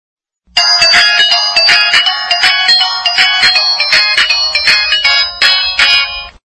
分类: 短信铃声
可爱短信铃声 特效人声